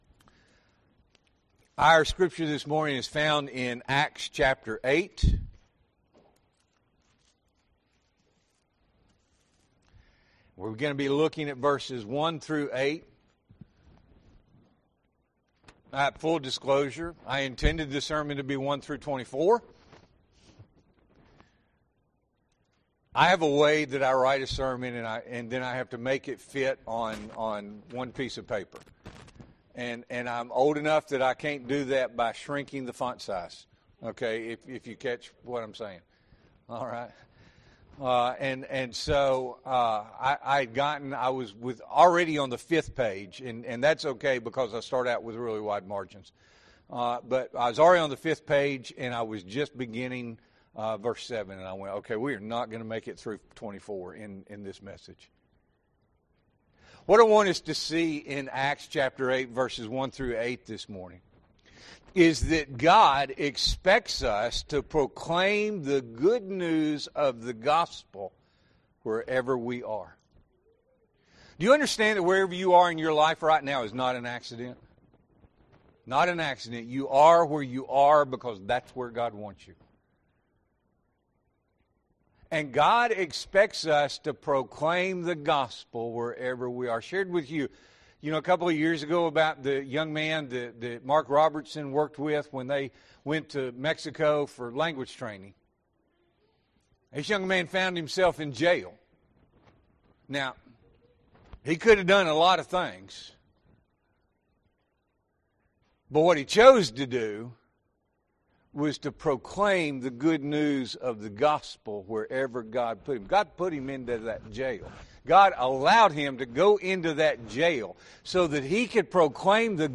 March 8, 2026 – Sunday School and Morning Worship